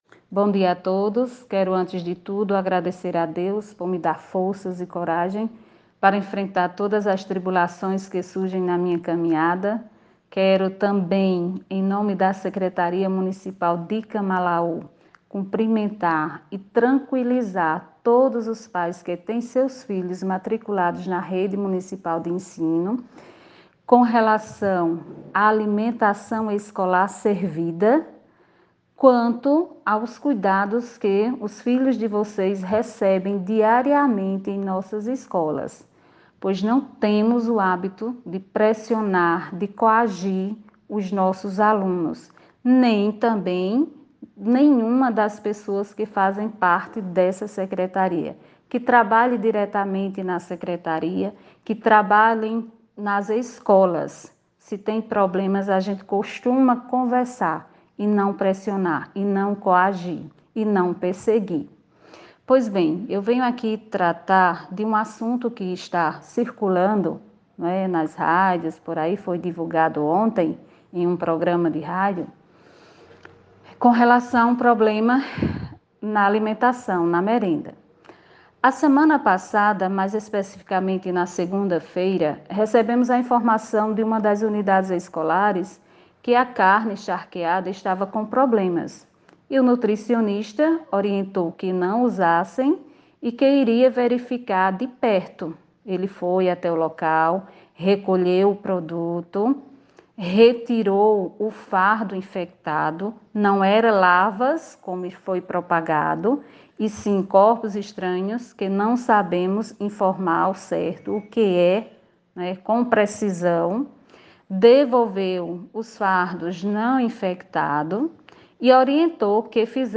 Nesta quarta-feira (06), a Secretária de Educação de Camalaú, Sandra Freitas, concedeu uma entrevista à rádio 95FM, a fim de prestar esclarecimentos sobre a situação da merenda escolar nas escolas do município.